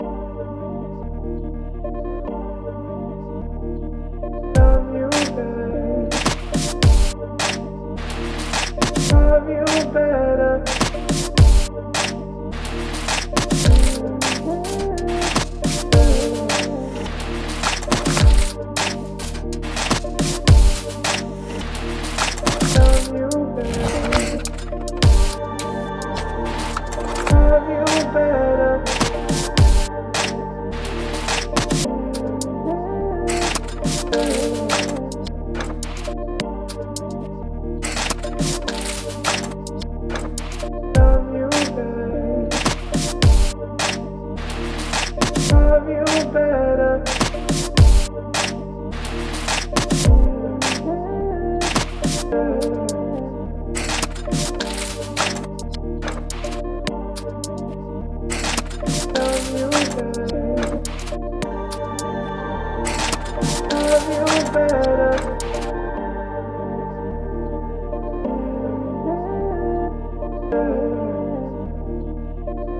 Here's a little groove I got going today using Simpler and some LoopMix derived Drum Loops...it should load fine on to the Push 3 or desktoxp.